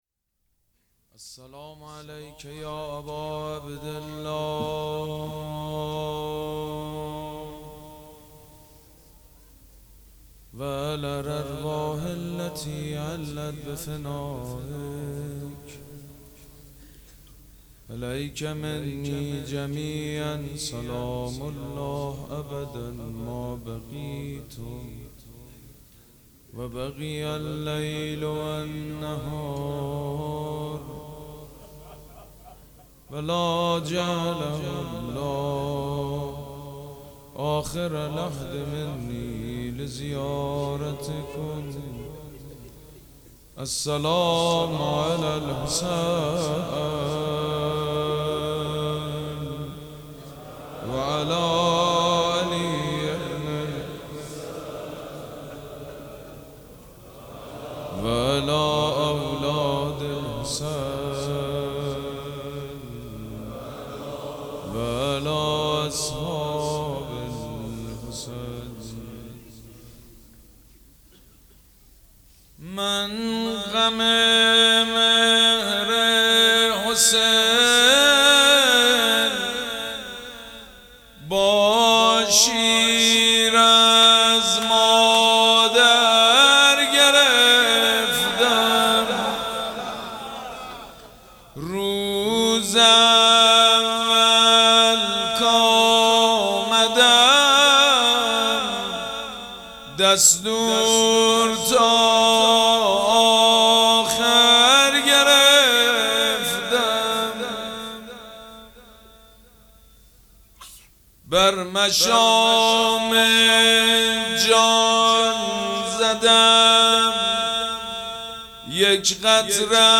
مراسم عزاداری شام شهادت حضرت رقیه سلام الله علیها
شعر خوانی
مداح
حاج سید مجید بنی فاطمه